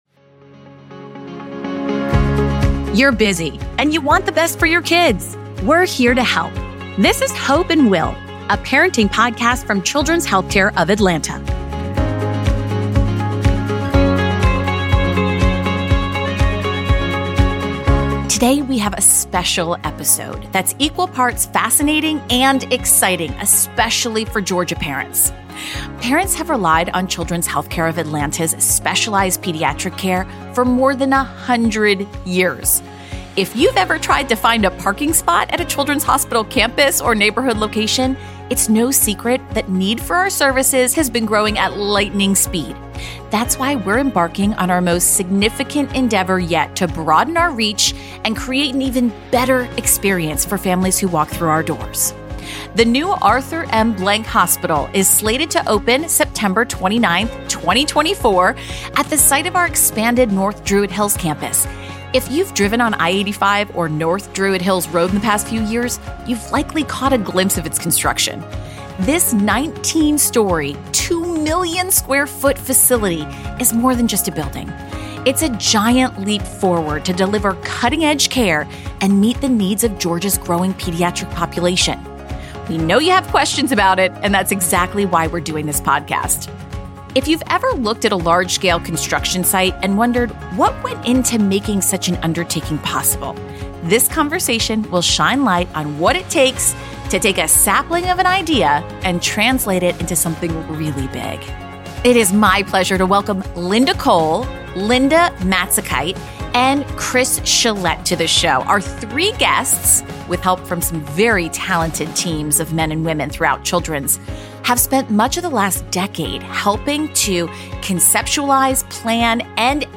If you’ve ever looked at a large-scale construction site and wondered what went into making such an undertaking possible, this conversation will shine a light on what it takes to take a sapling of an idea and translate it into something really, really big.